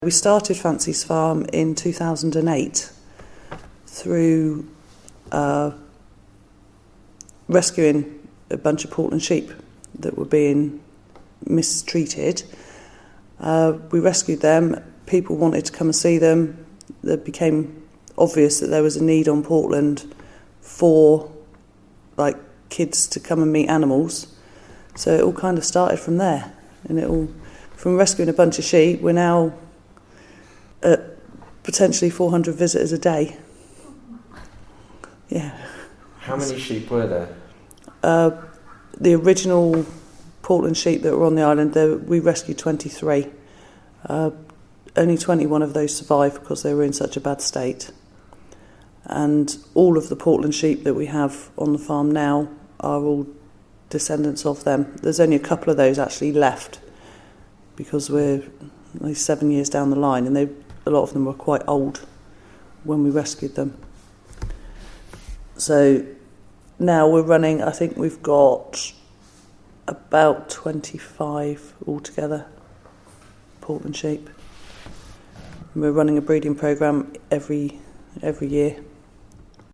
recorded these anecdotes, memories and experiences of Portland people.